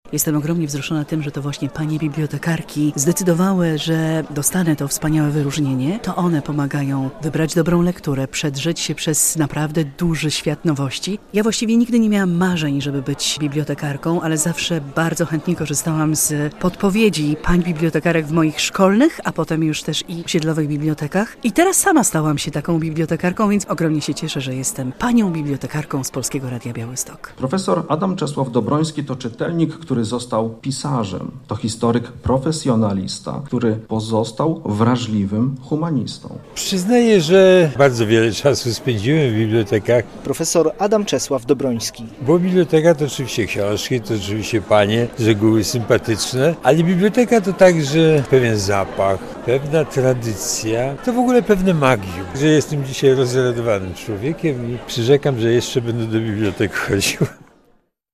W Książnicy Podlaskiej wręczono nagrodę i dyplomy Srebrnej Róży - relacja